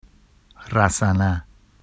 [rasana] n long rope